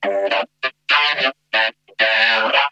VOC DOODUP 2.wav